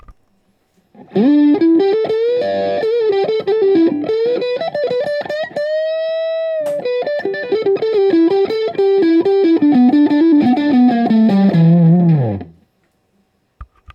All recordings in this section were recorded with an Olympus LS-10. Amp is an Axe-FX Ultra set to “Basic Brit 800” with no effects. Speaker is a QSC K12.
With the Muse riff out of the way, I changed to the neck pickup and noodled about with that wonderful thick tone.